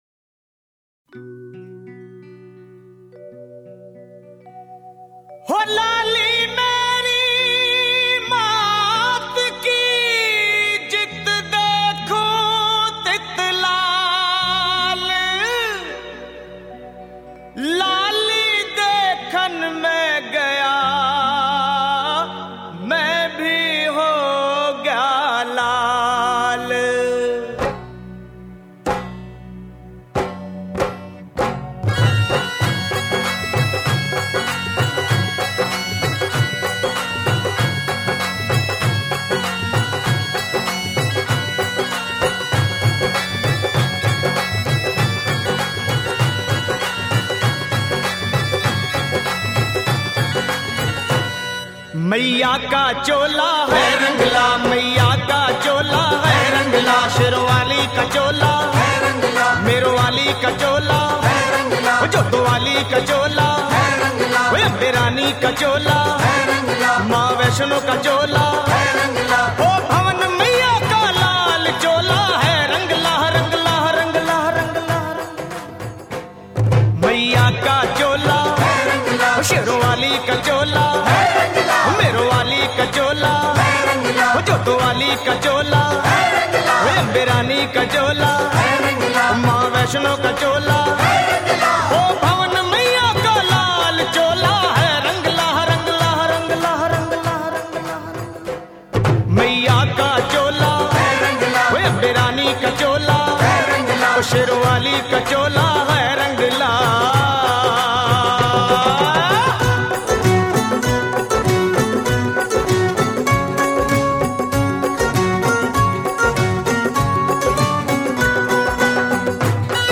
Mata bhajan
Devotional bhajan